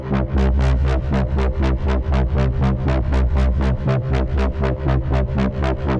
Index of /musicradar/dystopian-drone-samples/Tempo Loops/120bpm
DD_TempoDroneA_120-C.wav